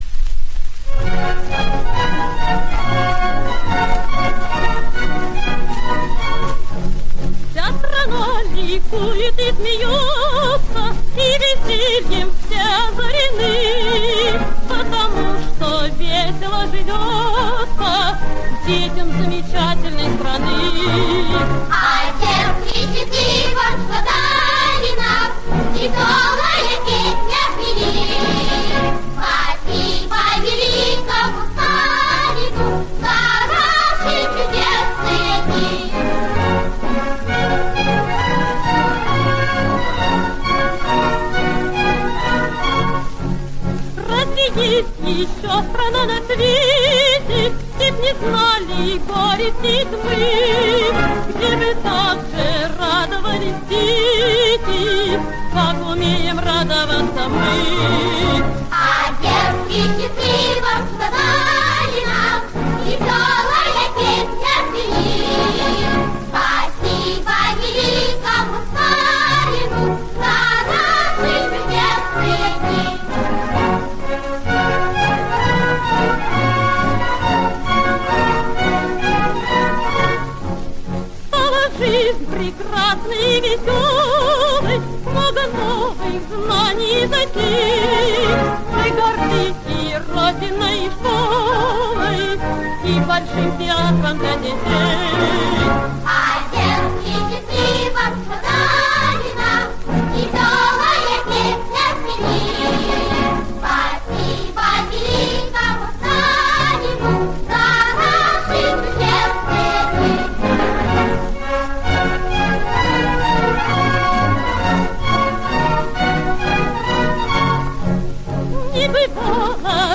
Soviet song about Stalin.